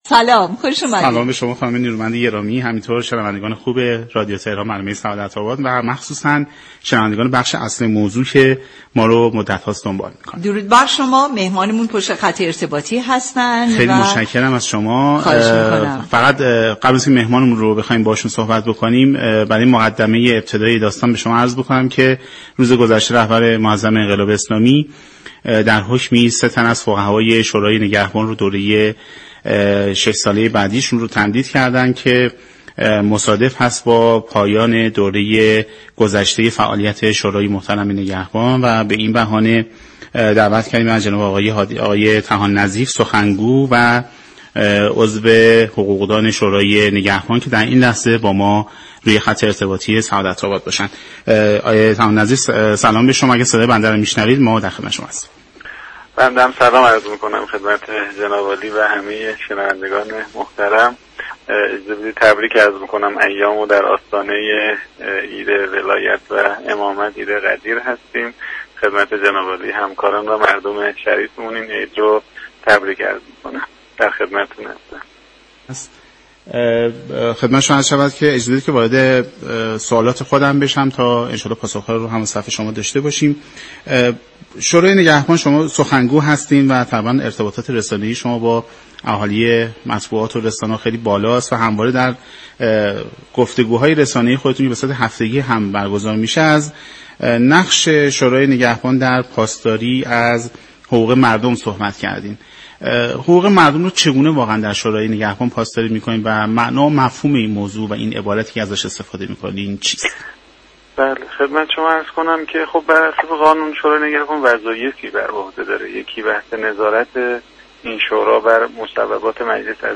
در همین راستا هادی طحان نظیف سخنگو و عضو حقوقی شورای نگهبان در آستانه فرارسیدن سالروز عید غدیر با برنامه سعادت اباد رادیو تهران گفت و گو كرد.